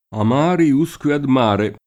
[lat. a m # ri 2S k U e ad m # re ]